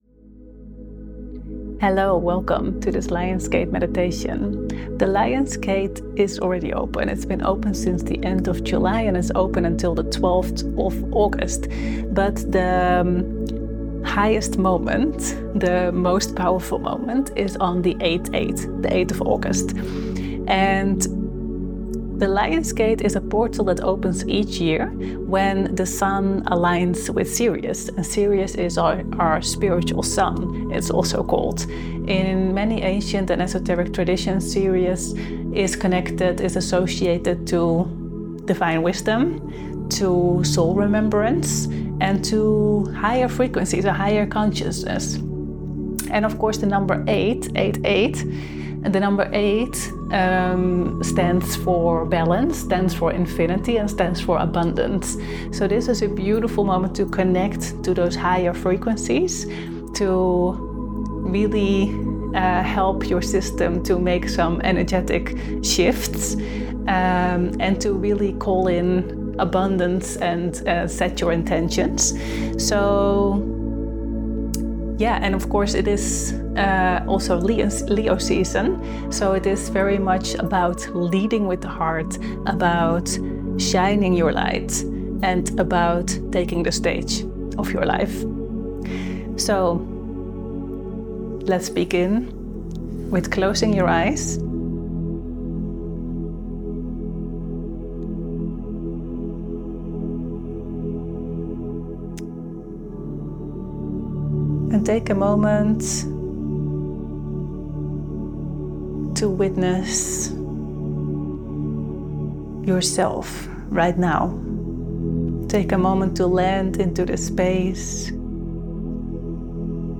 Lion's Gate Portal: a Meditation